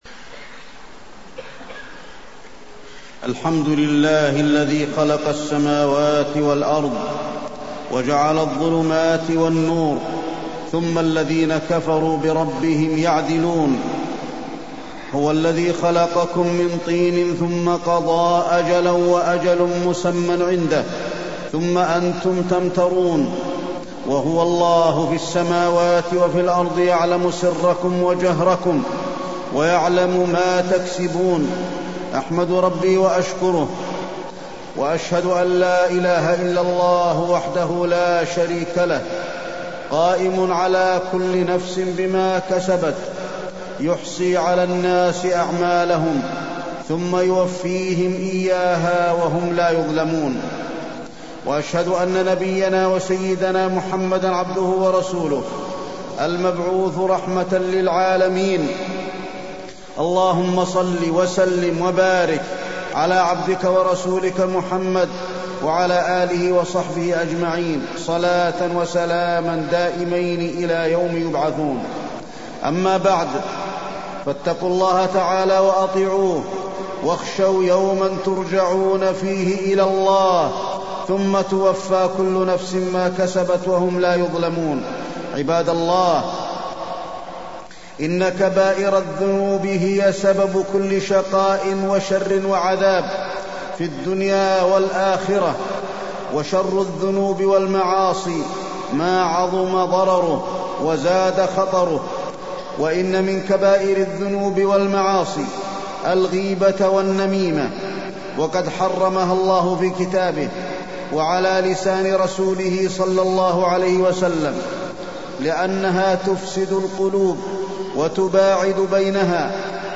تاريخ النشر ٢٠ رجب ١٤٢٣ هـ المكان: المسجد النبوي الشيخ: فضيلة الشيخ د. علي بن عبدالرحمن الحذيفي فضيلة الشيخ د. علي بن عبدالرحمن الحذيفي الغيبة والنميمة